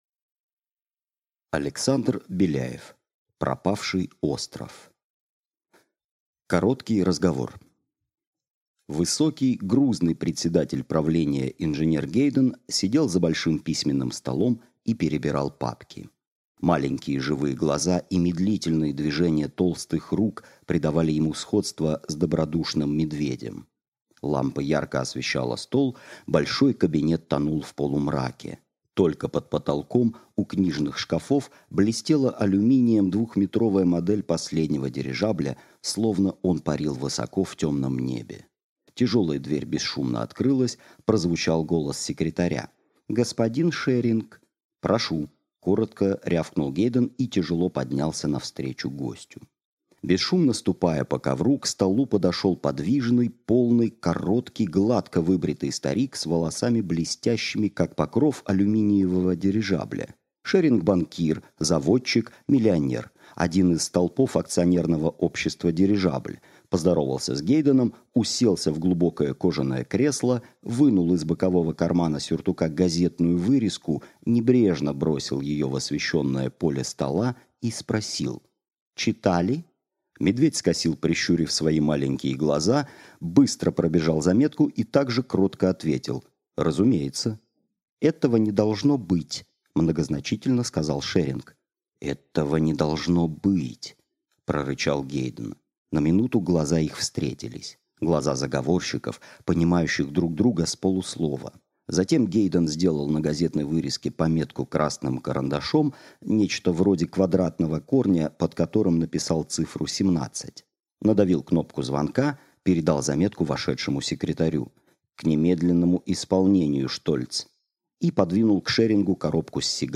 Аудиокнига Пропавший остров | Библиотека аудиокниг
Прослушать и бесплатно скачать фрагмент аудиокниги